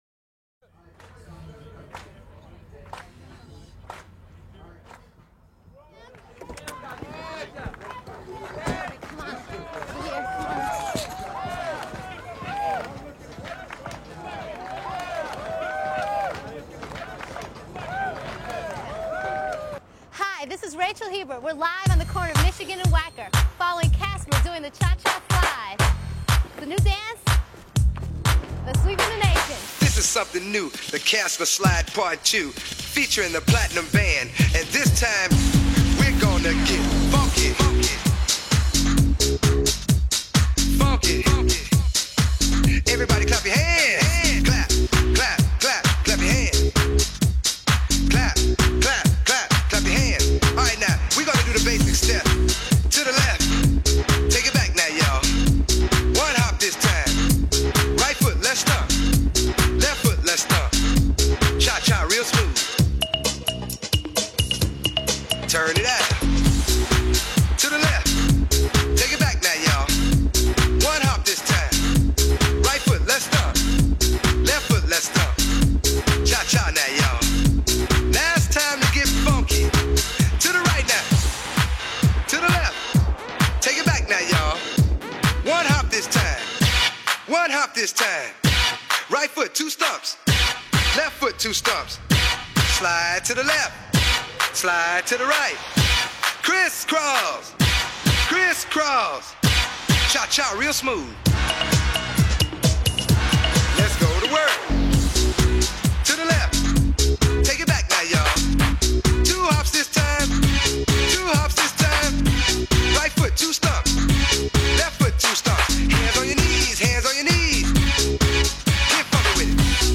a very active song that fits the early morning mood.